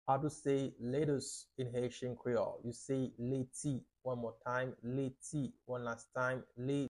How to say “Lettuce” in Haitian Creole - Leti pronunciation by a native Haitian Teacher
How-to-say-Lettuce-in-Haitian-Creole-Leti-pronunciation-by-a-native-Haitian-Teacher.mp3